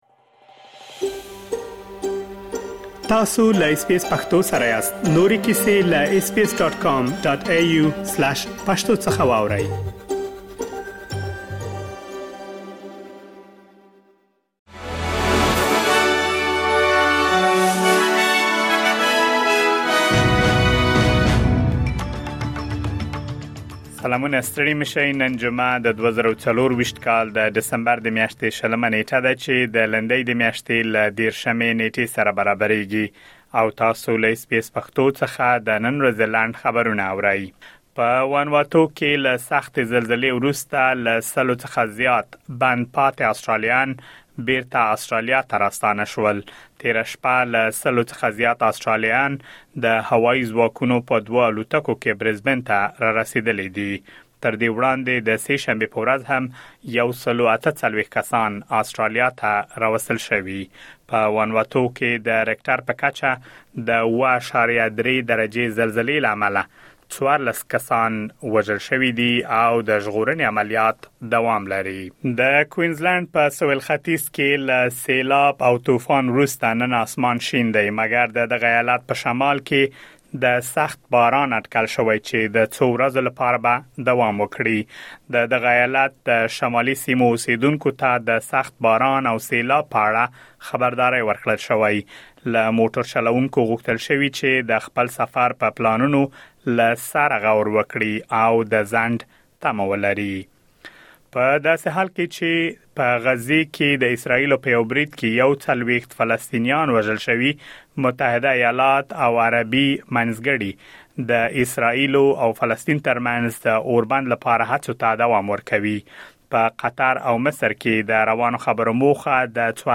د اس بي اس پښتو د نن ورځې لنډ خبرونه |۲۰ ډسمبر ۲۰۲۴